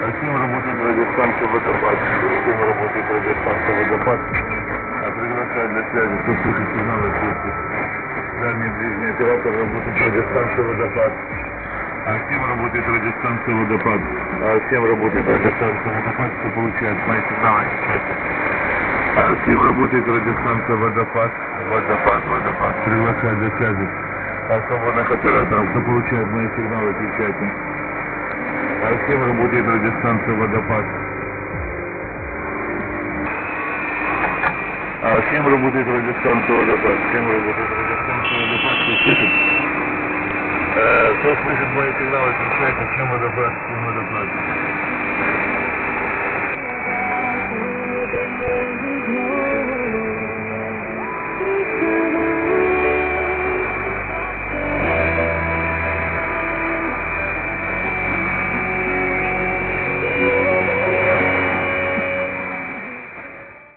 I can hear these stations in Paimio-location in our DX-camp,  with our very long wires.
Here a few recordings of unknown Russian MW-pirates
mp3  Heard in Naantali!